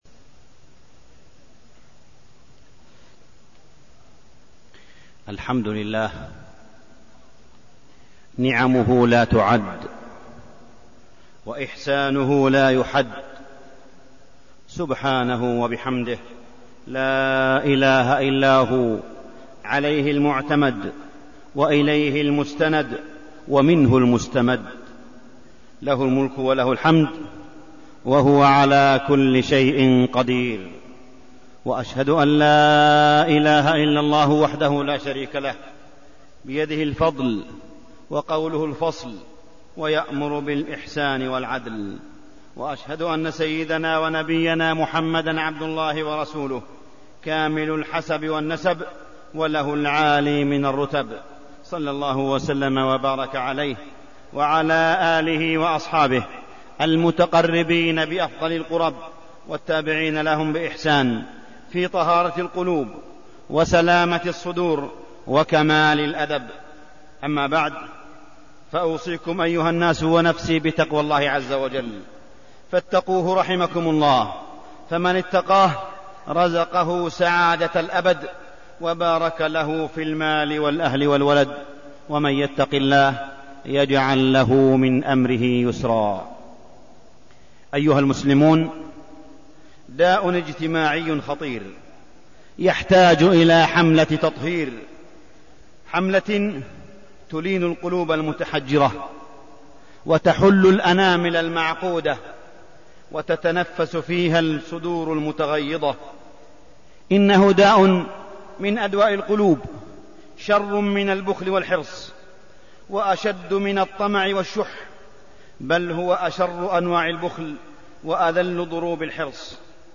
تاريخ النشر ٢ جمادى الآخرة ١٤١٨ هـ المكان: المسجد الحرام الشيخ: معالي الشيخ أ.د. صالح بن عبدالله بن حميد معالي الشيخ أ.د. صالح بن عبدالله بن حميد داء الحسد